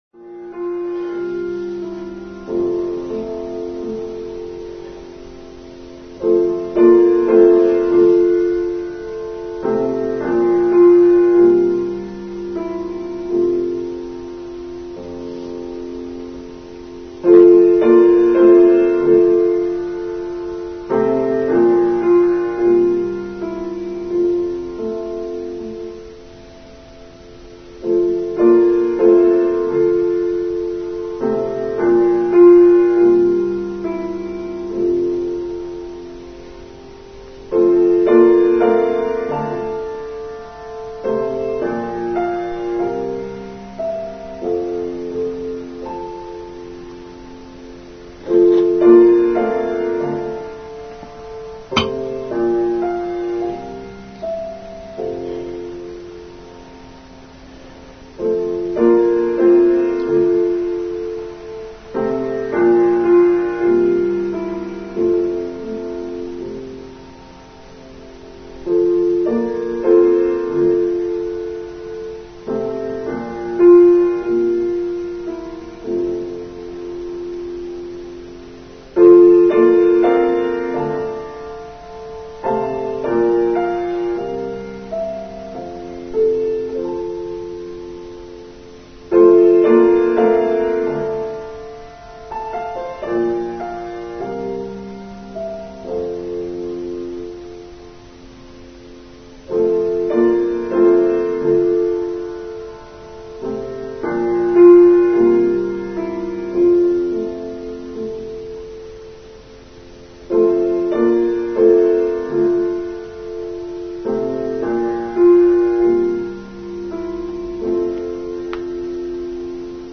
Online service for One World Week, 18th October 2020
Prelude